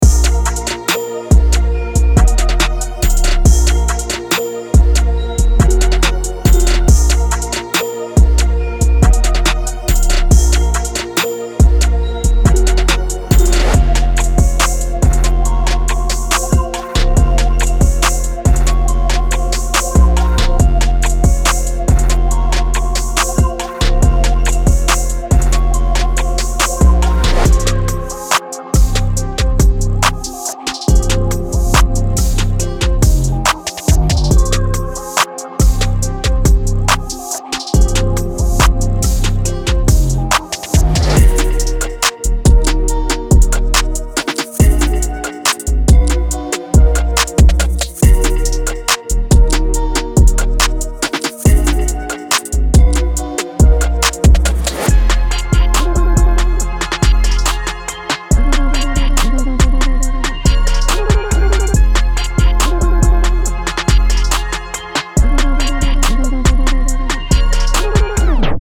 dark, stylish, and imbued with an city aesthetic.
atmospheric, melancholic, and energized cities!